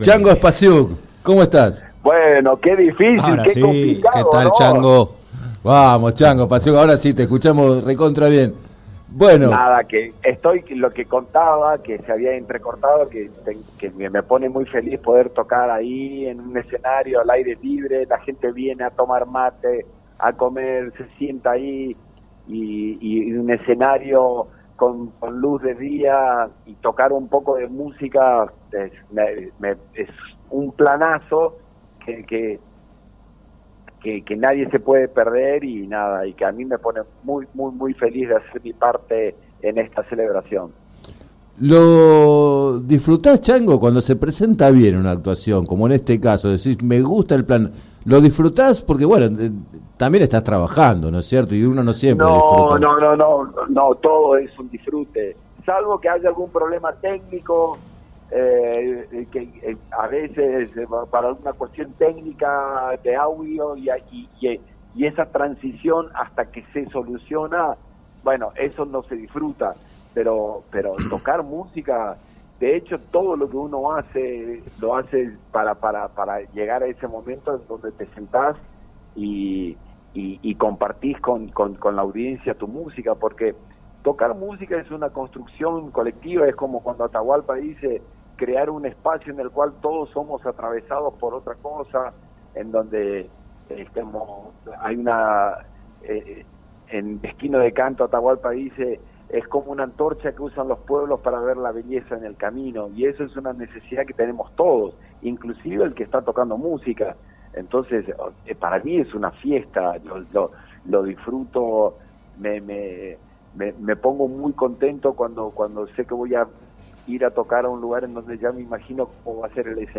Entrevistado en el programa “Planeta Terri” de FM Líder 97.7, Spasiuk contó que visita muy seguido Luján por razones familiares e invitó a compartir la fiesta del próximo fin de semana largo, a la que calificó de “planazo”.